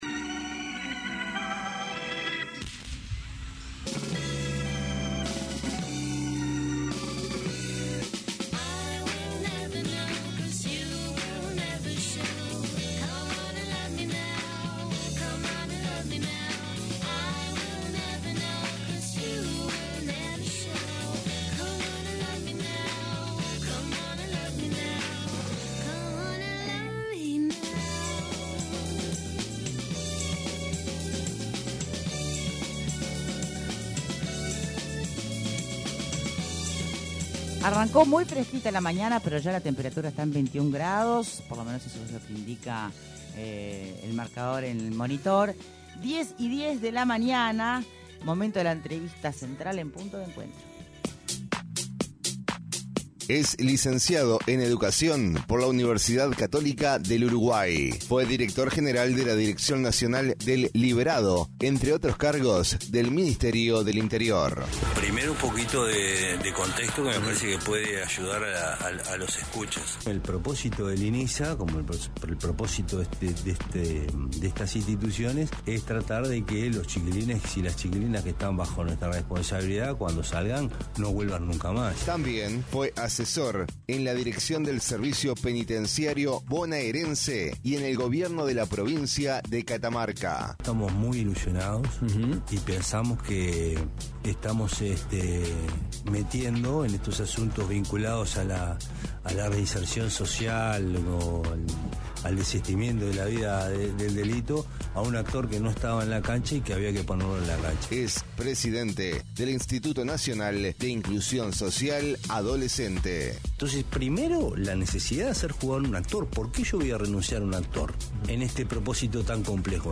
ENTREVISTA: JAIME SAAVEDRA
En entrevista con Punto de Encuentro, el presidente del Instituto Nacional de Inclusión Social Adolescente (Inisa), Jaime Saavedra se refirió a la conformación del directorio del instituto donde por primera vez en la historia de Uruguay, en un servicio descentralizado, el oficialismo es minoría: “Es un gran mensaje”.